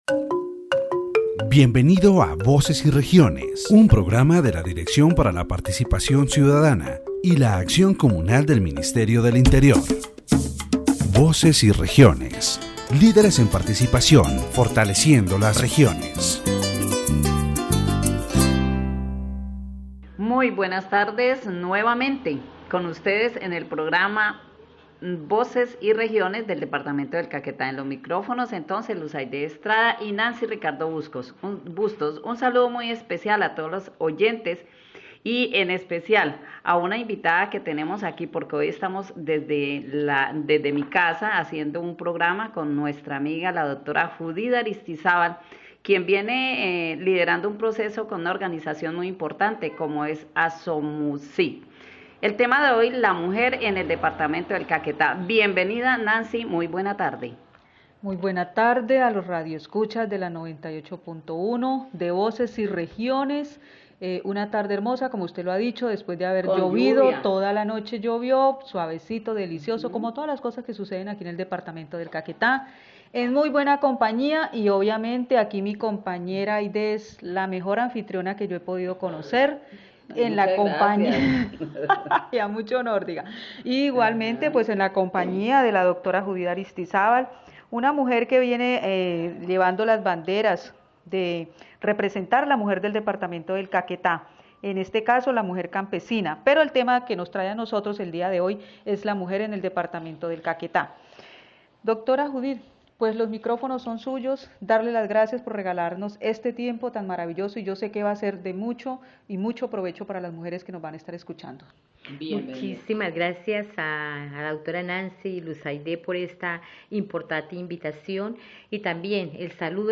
The radio program "Voices and Regions" of the Directorate for Citizen Participation and Communal Action of the Ministry of the Interior focuses on the participation and empowerment of women in the Department of Caquetá.